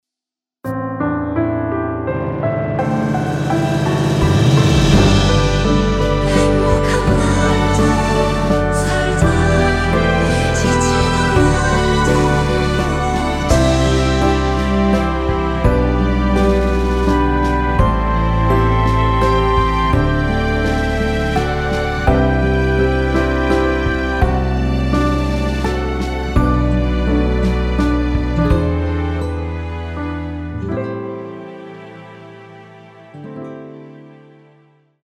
이곡의 코러스는 미리듣기에 나오는 부분이 전부 입니다.다른 부분에는 코러스가 없습니다.(미리듣기 확인)
원키에서(-1)내린 코러스 포함된 MR입니다.
앞부분30초, 뒷부분30초씩 편집해서 올려 드리고 있습니다.
중간에 음이 끈어지고 다시 나오는 이유는